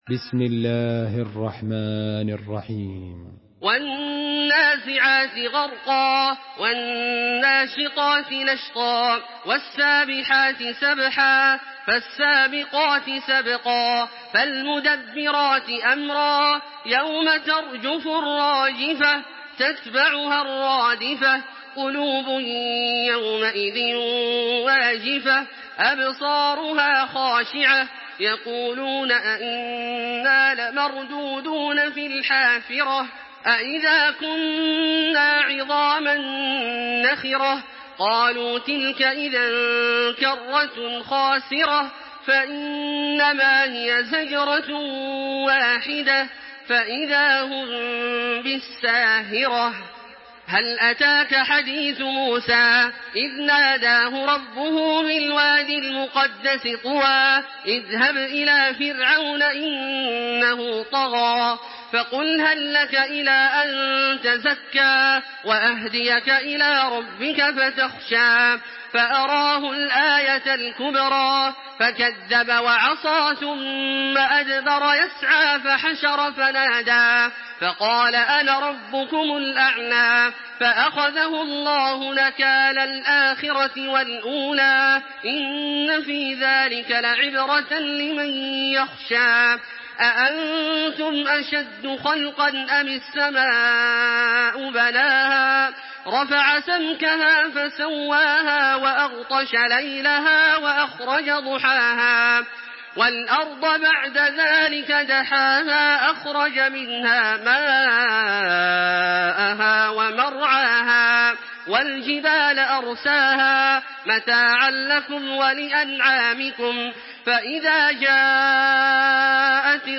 Surah An-Naziat MP3 by Makkah Taraweeh 1426 in Hafs An Asim narration.
Murattal